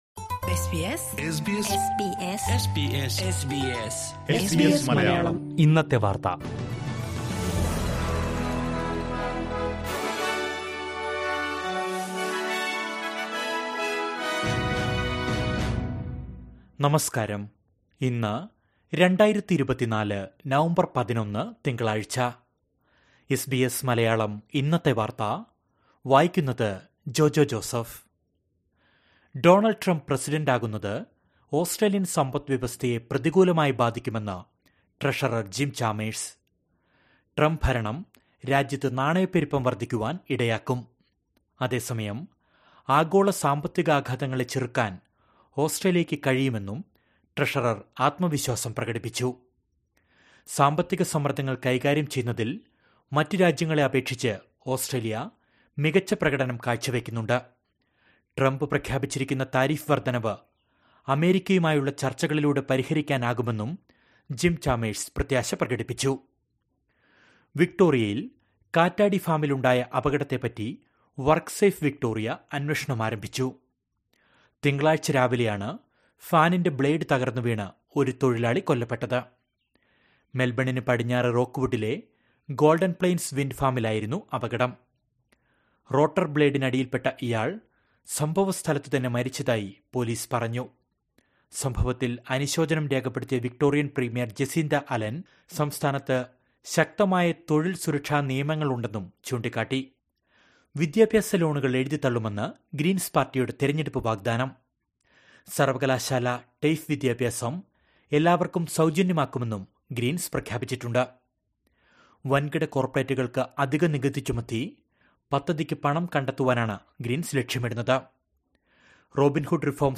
2024 നവംബര്‍ 11ലെ ഓസ്‌ട്രേലിയയിലെ ഏറ്റവും പ്രധാന വാര്‍ത്തകള്‍ കേള്‍ക്കാം...